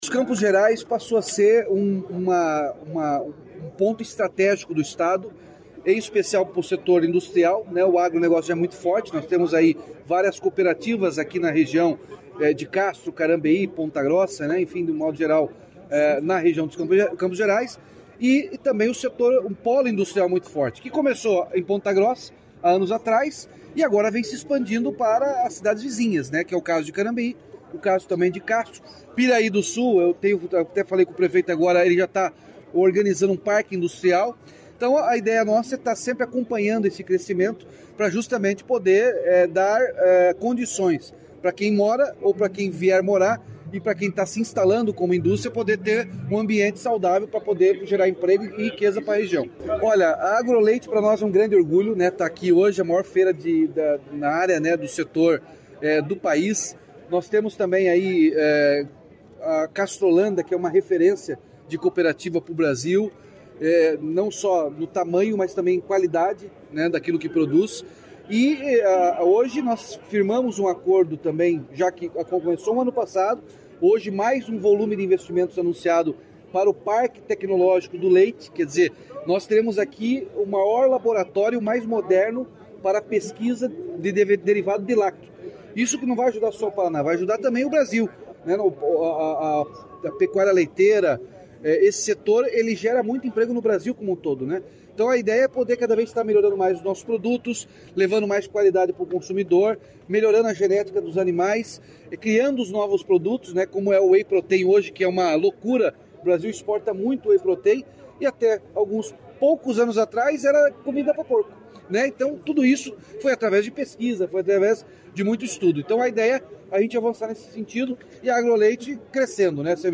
Sonora do governador Ratinho Junior sobre o pacote de investimentos em infraestrutura em Castro